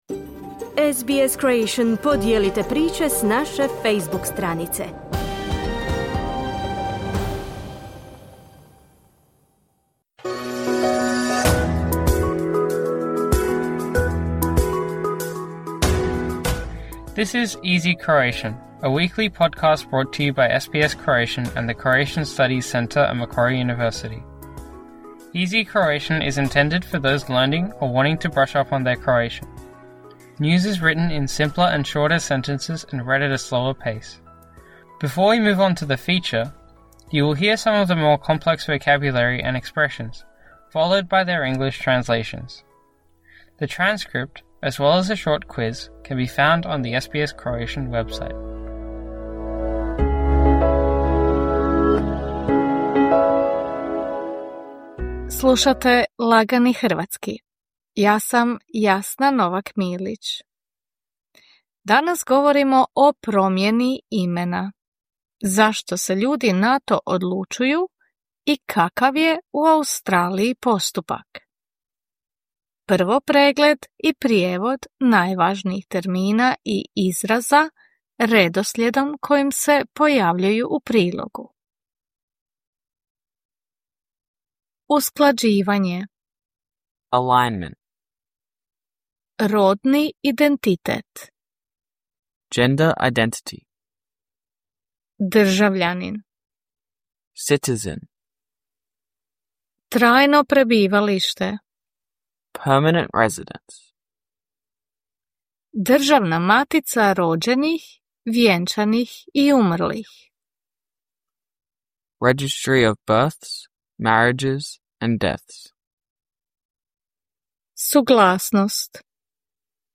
“Easy Croatian” is intended for those who want to brush up on their Croatian. The news is written in simpler and shorter sentences and read at a slower pace.